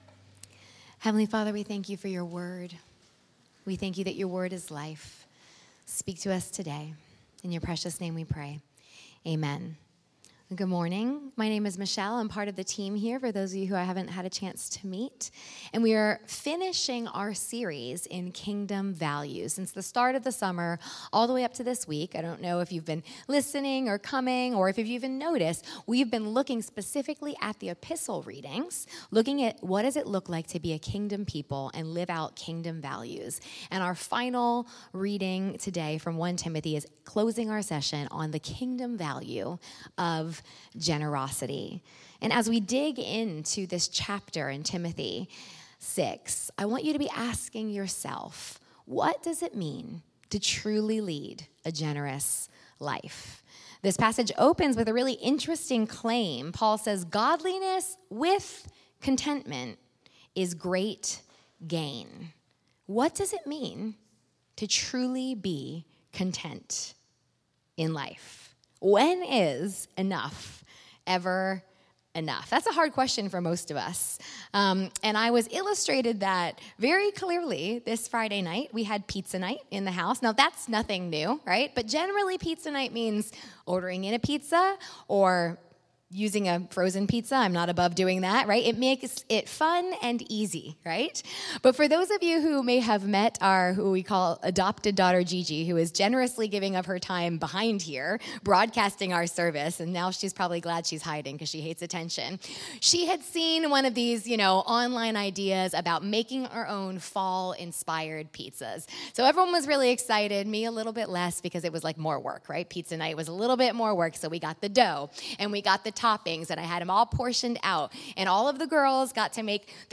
Sermon
St Peter the Fisherman, New Smyrna Beach, Florida.